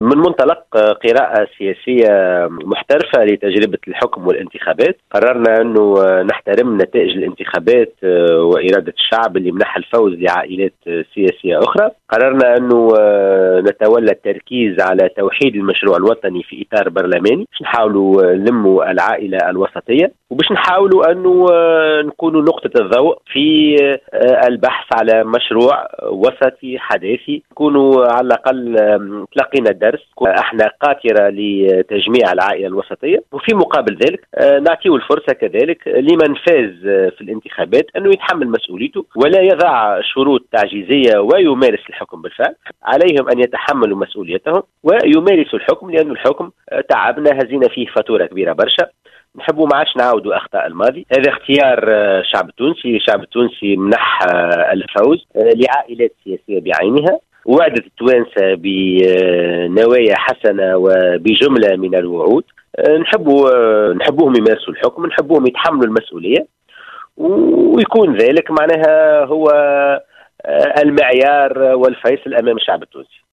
dans une déclaration accordée ce mercredi 9 octobre 2019, à Mosaïque FM